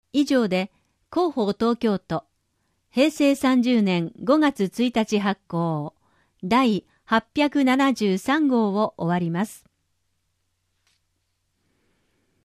「広報東京都音声版」は、視覚に障害のある方を対象に「広報東京都」の記事を再編集し、音声にしたものです。
終わりアナウンス（MP3：98KB） 12秒